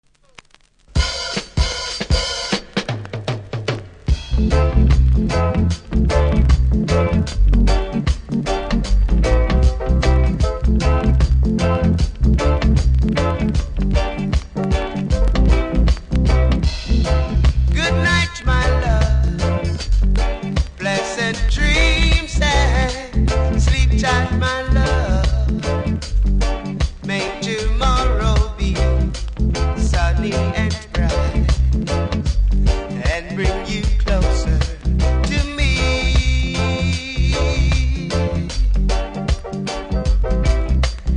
キズは多めですがノイズはそれほどでもなくプレイ可レベルだと思いますので試聴で確認下さい。